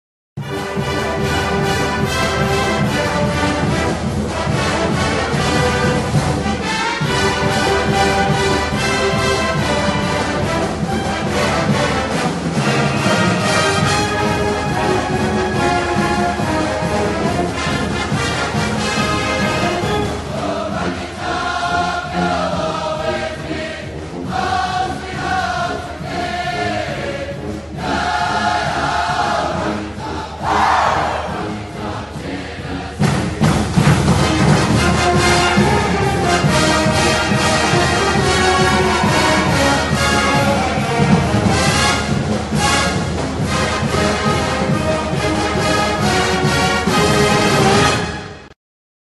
marching band